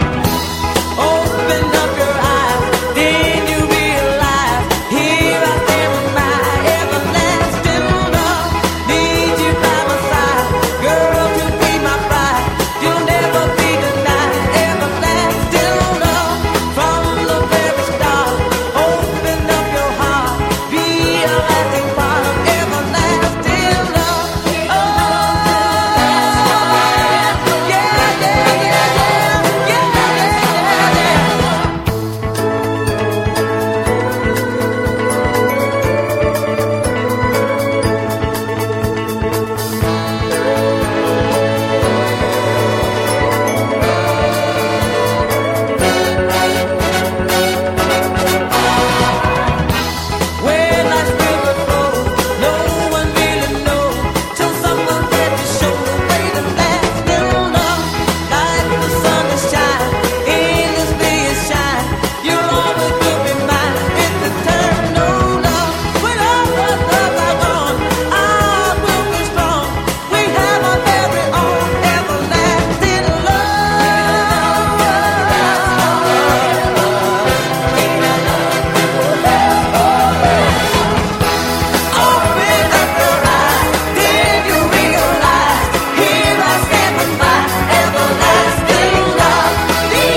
清涼感溢れるメロウ・グルーヴ
汗だくファンク・チューン